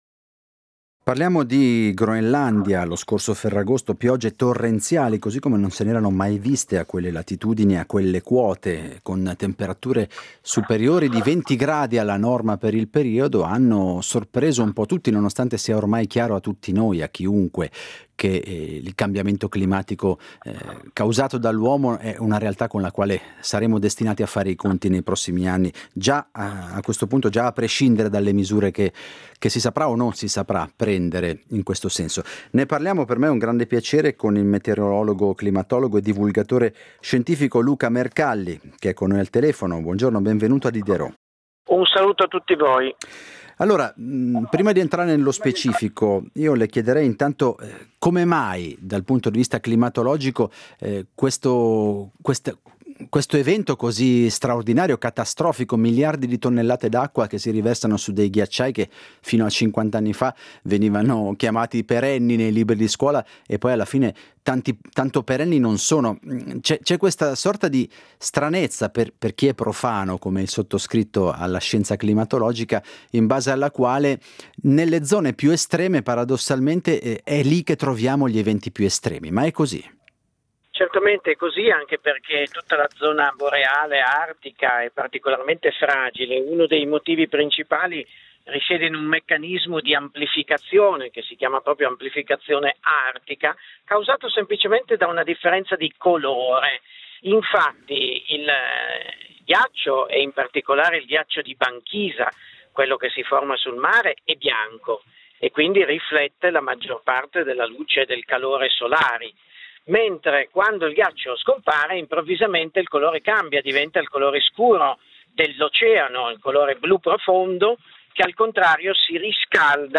Se ormai è chiaro a tutti che il cambiamento climatico è un fenomeno reale, rapido e con il quale saremo tutti costretti a fare i conti, le notizie delle recenti piogge torrenziali in Groenlandia – è la prima volta che accade - hanno sorpreso anche i climatologi. Indaghiamo l’inedito e preoccupante fenomeno con il meteorologo, climatologo e divulgatore scientifico Luca Mercalli.